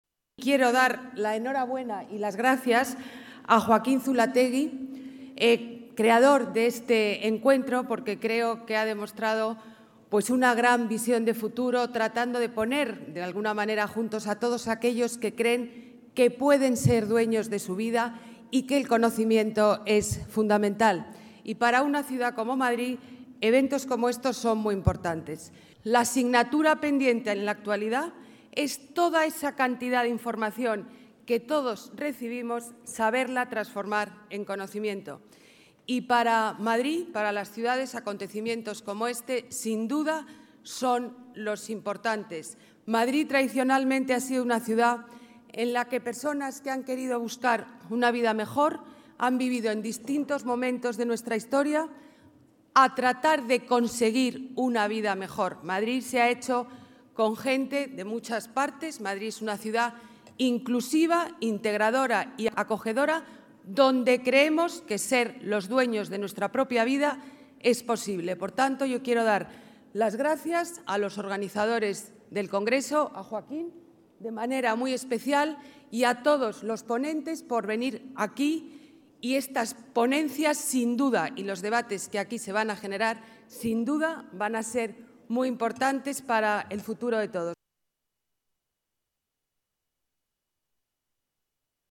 Nueva ventana:Declaraciones Ana Botella: Congreso Mentes Brillantes